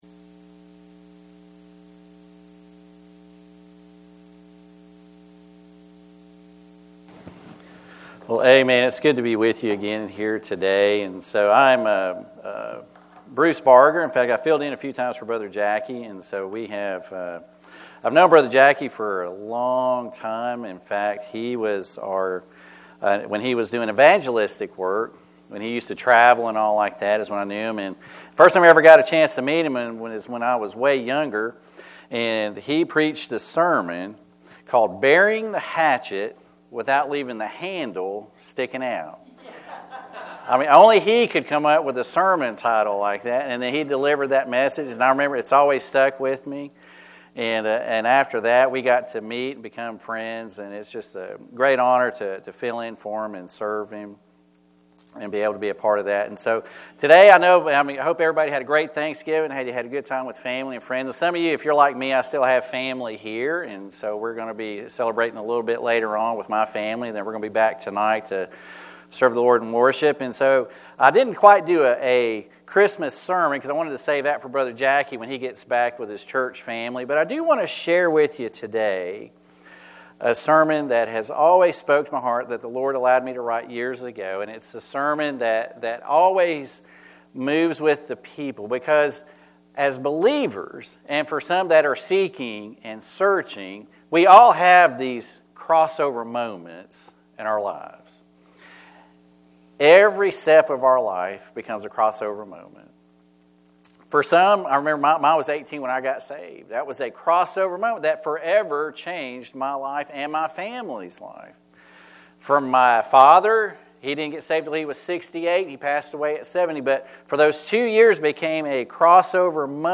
Sermons - Calvary Baptist Church